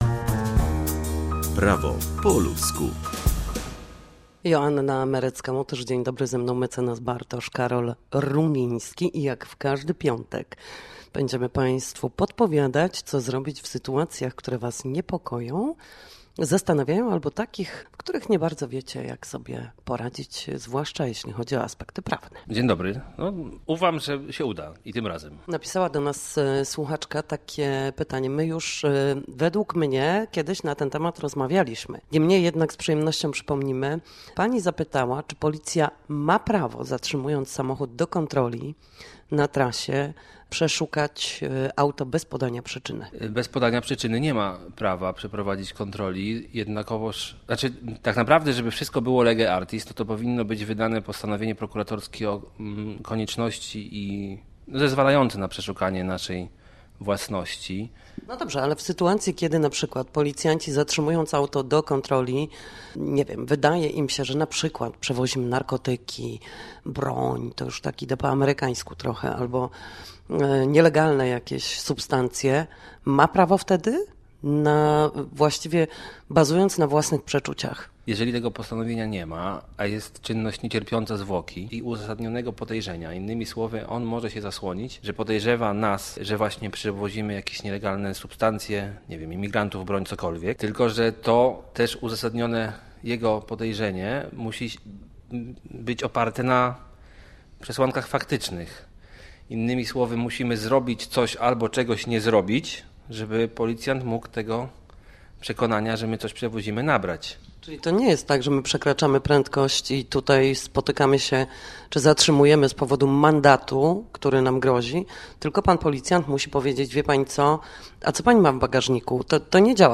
W każdy piątek o godzinie 7:20 i 13:40 na antenie Studia Słupsk przybliżamy państwu meandry prawa. Nasi goście, prawnicy, odpowiadać będą pytania dotyczące zachowania w sądzie czy podstawowych zagadnień prawniczych.